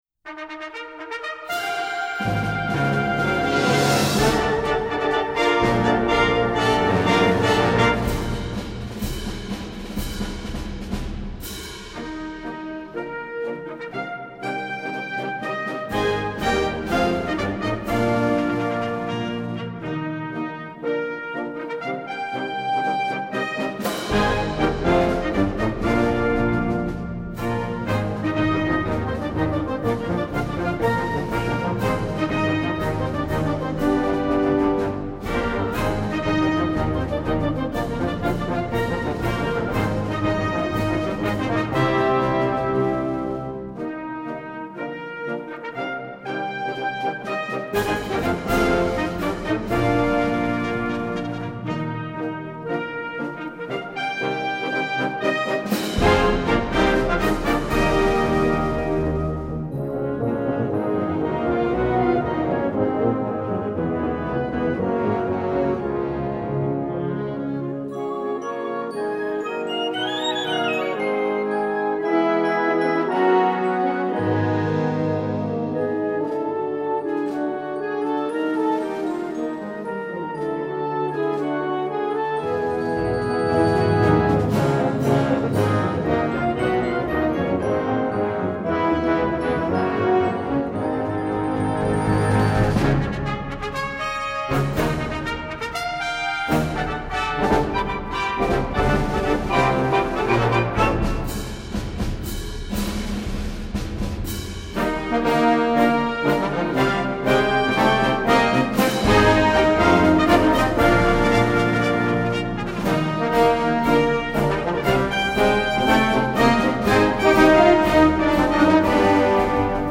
Gattung: Marsch
2:20 Minuten Besetzung: Blasorchester PDF